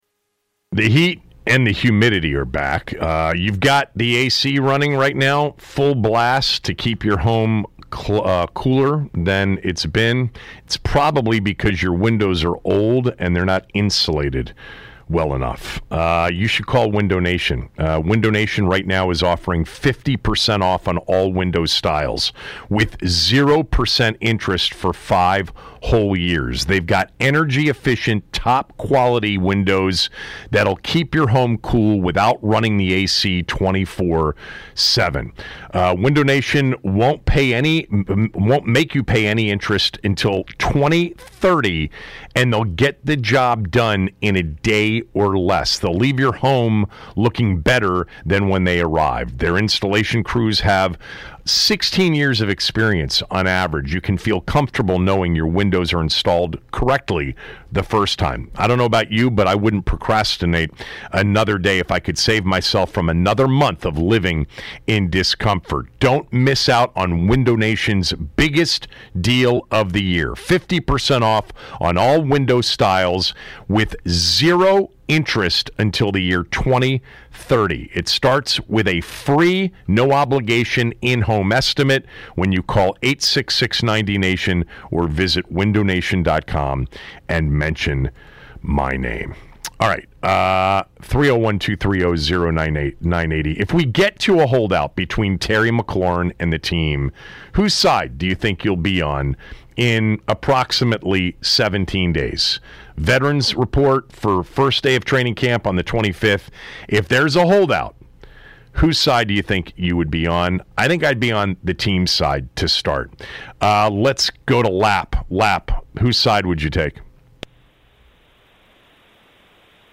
Callers give their opinion on whether they would side with the Commanders or Terry McLaurin if he were to hold out for a new contract.